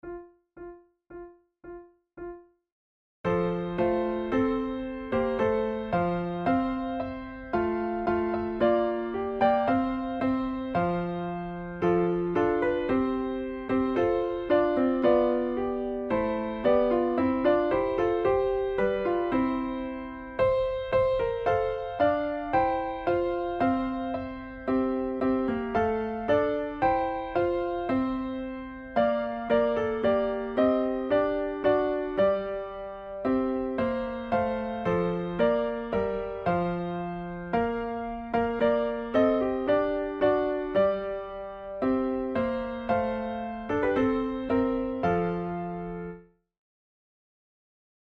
A Christmas carol
Categories: Christmas carols Hymn tunes Difficulty: easy